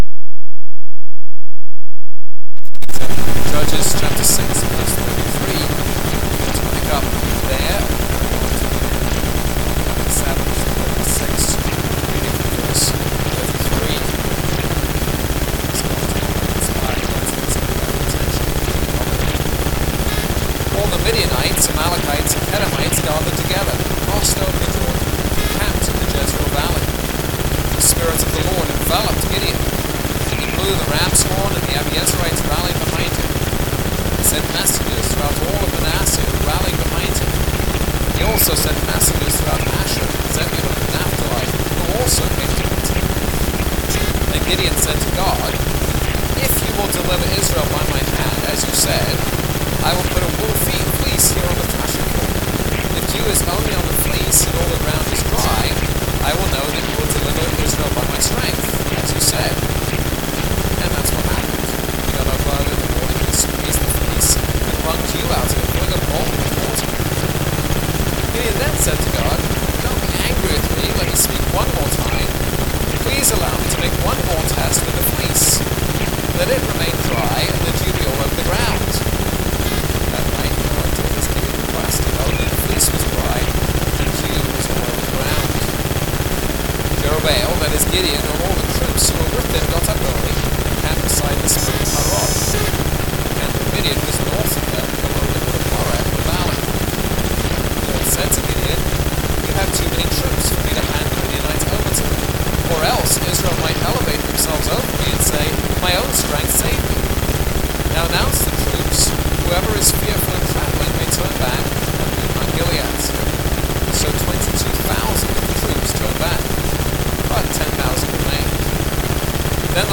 This is a sermon on Judges 6:33-40, Judges 7 - Judges 7:1-25